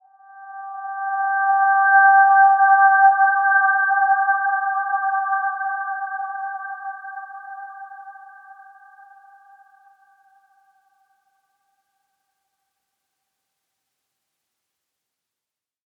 Dreamy-Fifths-G5-mf.wav